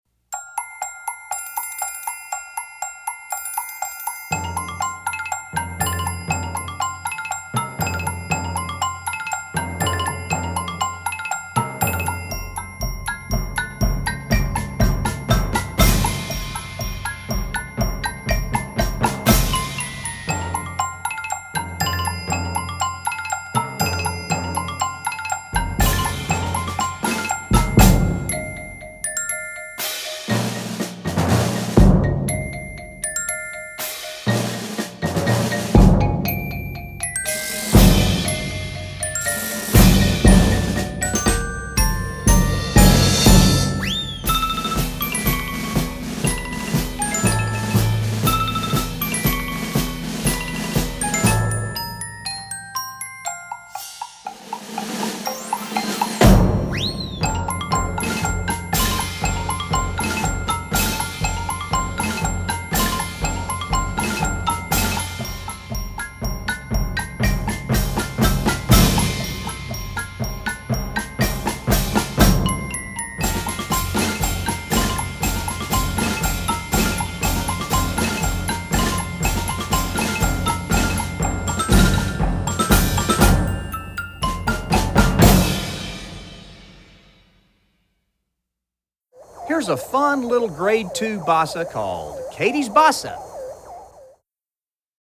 Voicing: 7-8 Percussion